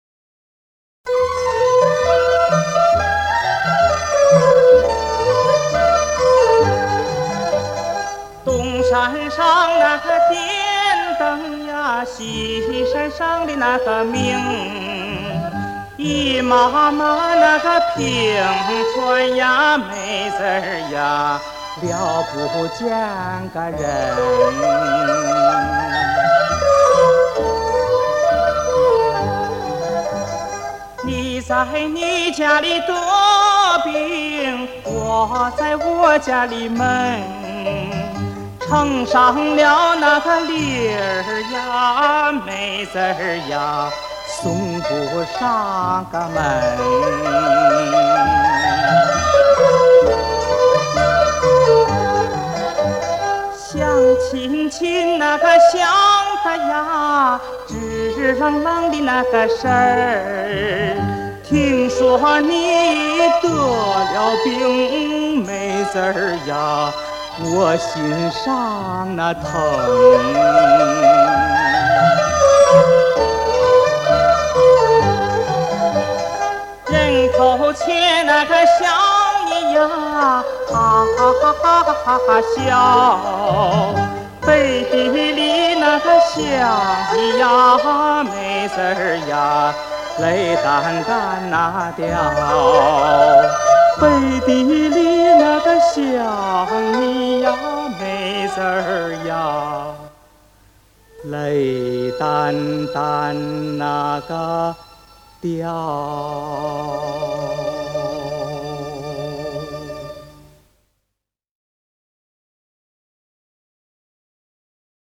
[12/12/2015]男高音歌唱家柳石明演唱的山西民歌《东山上点灯西山上明》 激动社区，陪你一起慢慢变老！